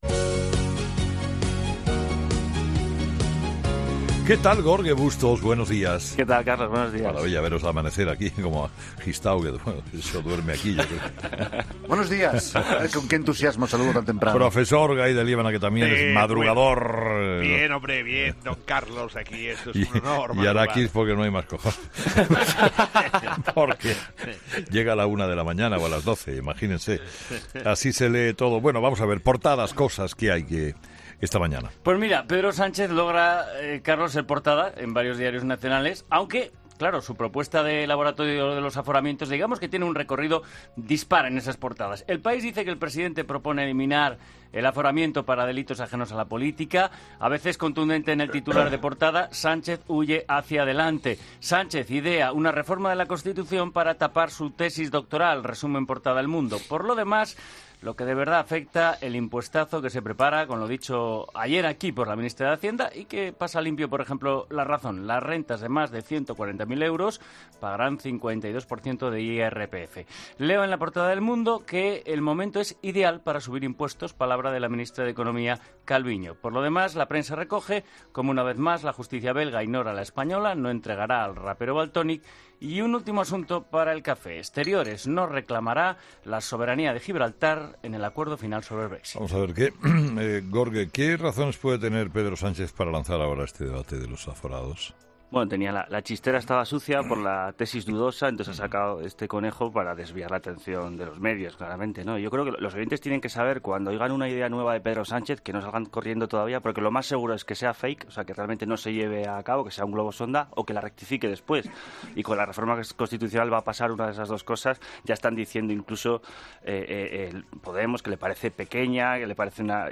La revista de prensa en el primer café de la mañana en 'Herrera en COPE'